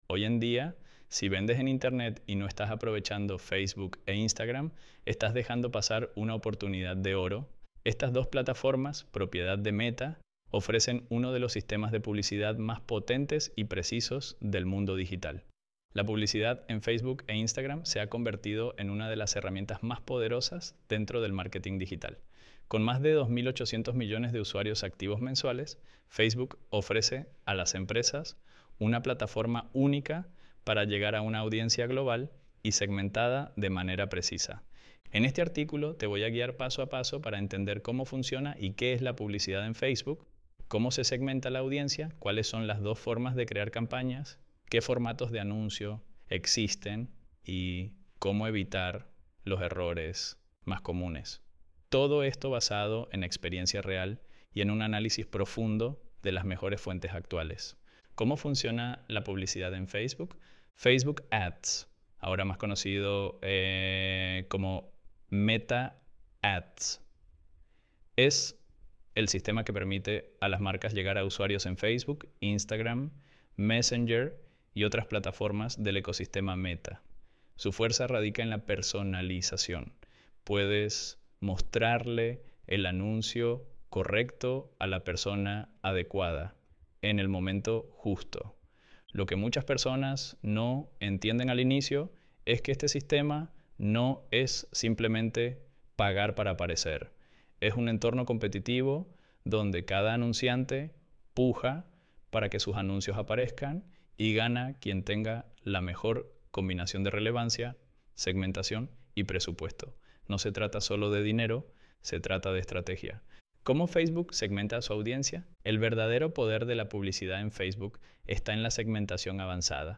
ElevenLabs_Hoy_en_dia_si_vendes_en.mp3